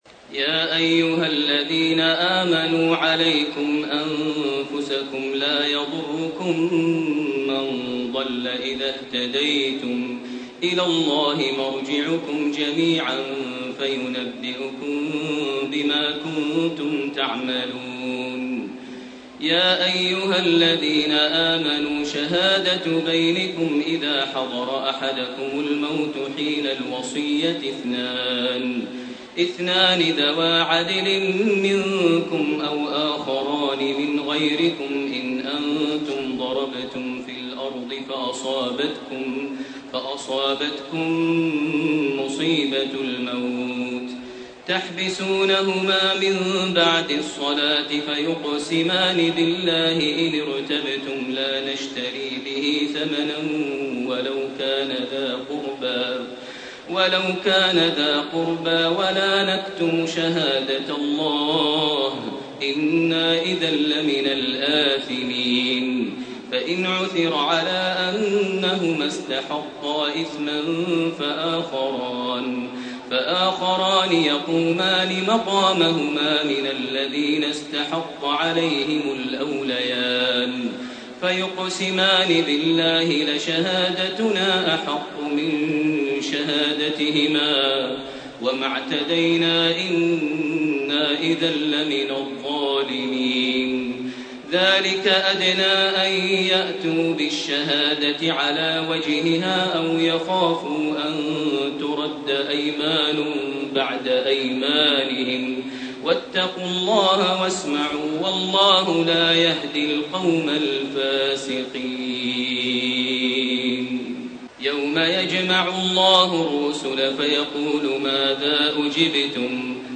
سورتي المائدة من 105 الي اخرها وسورة الأنعام 1 - 71 > تراويح ١٤٣٢ > التراويح - تلاوات ماهر المعيقلي